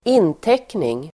Uttal: [²'in:tek:ning]